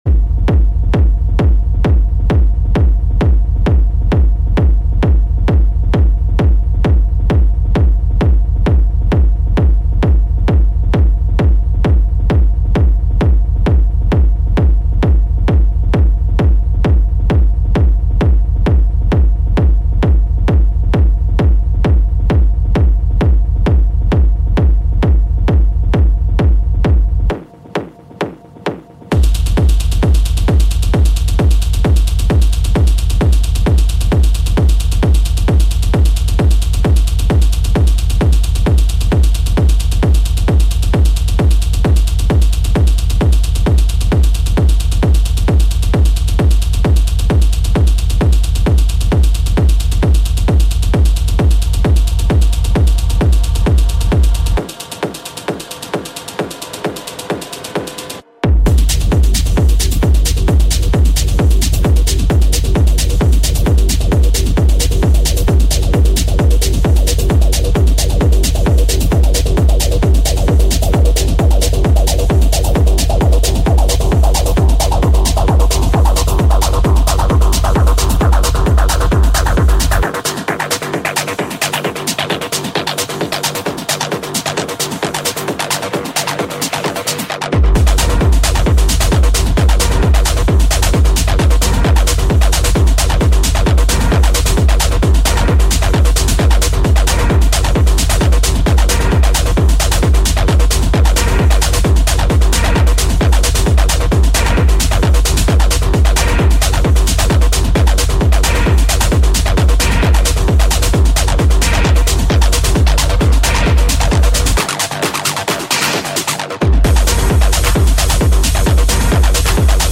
Acid Techno Mix
Acid-Techno-Mix.mp3